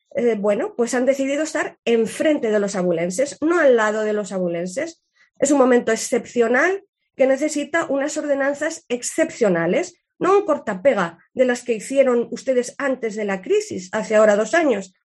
Sonsoles Sánchez-Reyes, portavoz PP. Pleno ordenanzas 2022